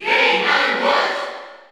Category: Crowd cheers (SSBU) You cannot overwrite this file.
Mr._Game_&_Watch_Cheer_Spanish_PAL_SSBU.ogg